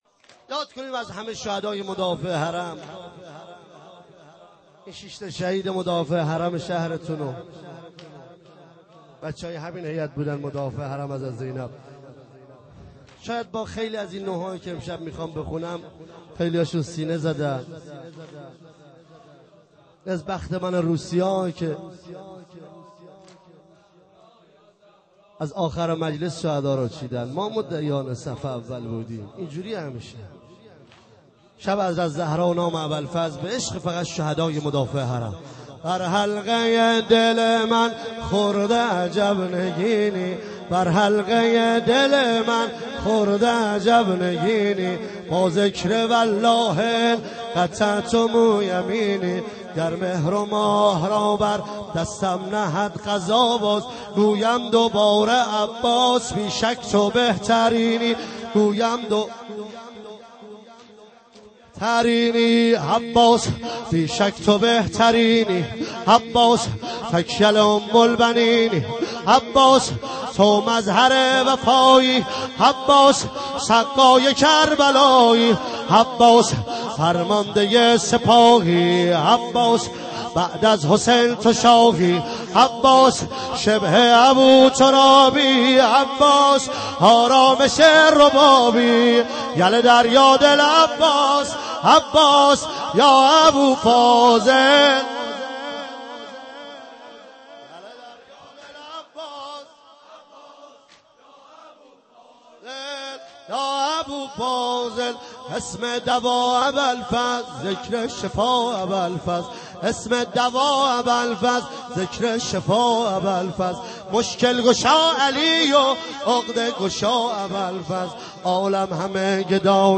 فاطمیه 95
مداحی